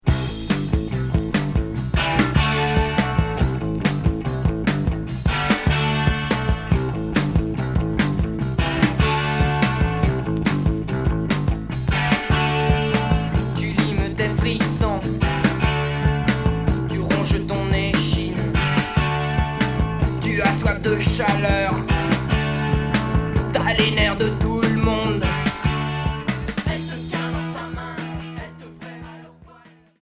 Rock new wave Premier 45t retour à l'accueil